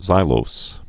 (zīlōs)